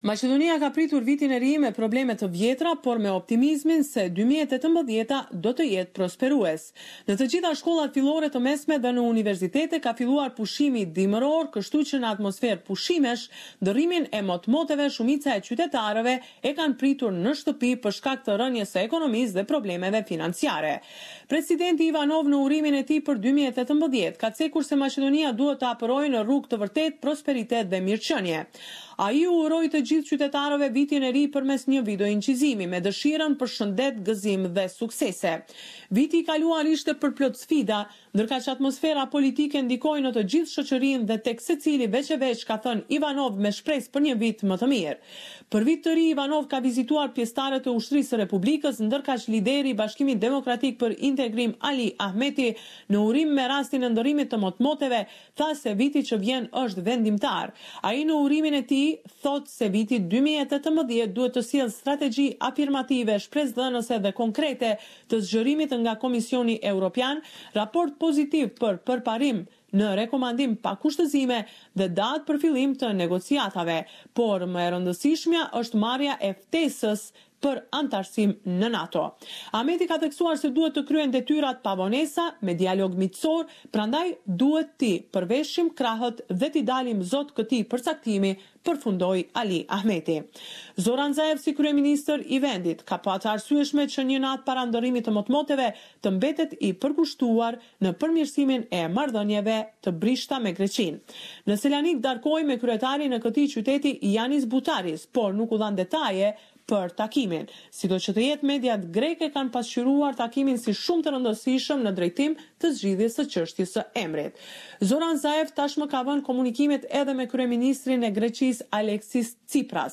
The weekly report with the latest developments in Macedonia